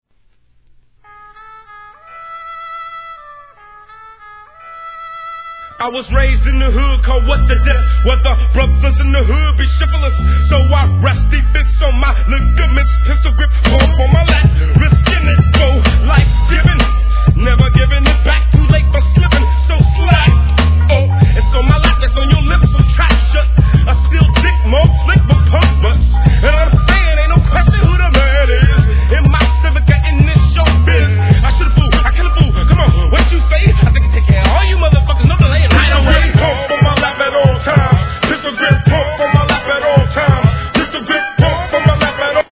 G-RAP/WEST COAST/SOUTH
様々なGANGSTA RAPで使用された定番FUNKネタでのWEST COAST CLASSIC!!